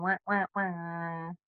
Remix Siren